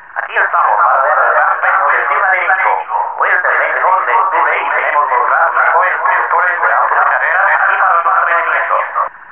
announce.wav